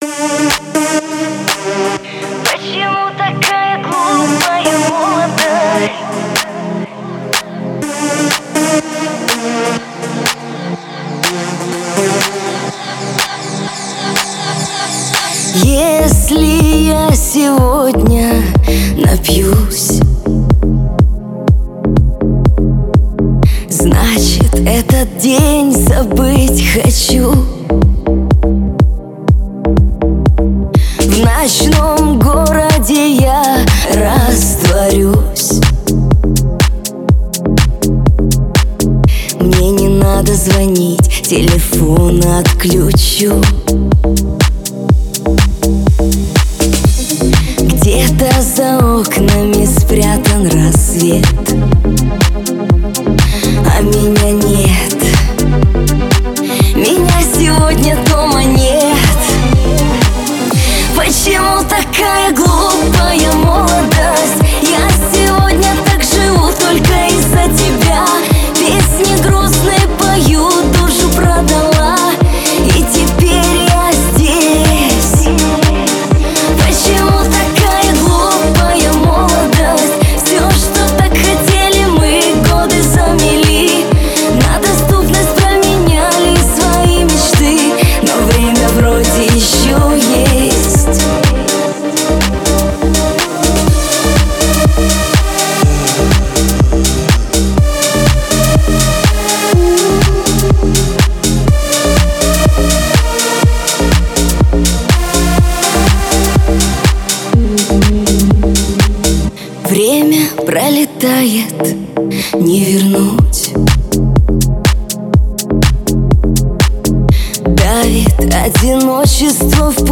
Шансон песни
популярный шансон